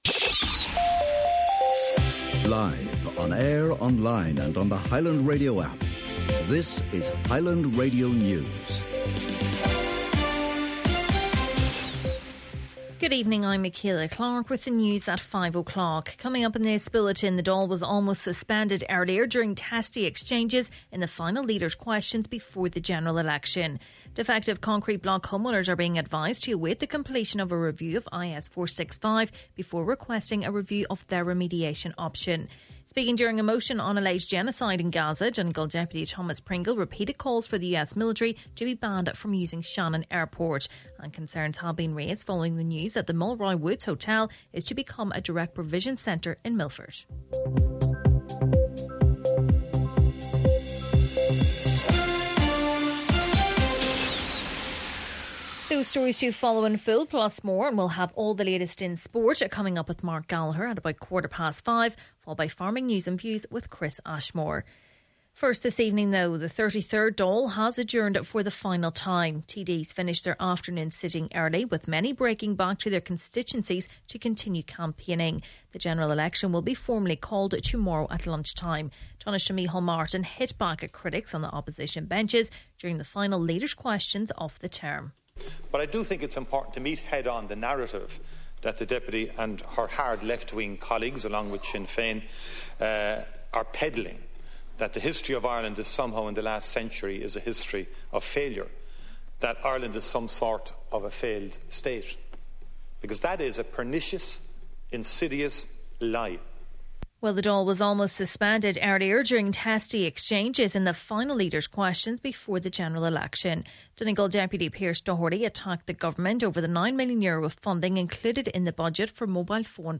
Main Evening News, Sport and Farming News – Thursday, November 7th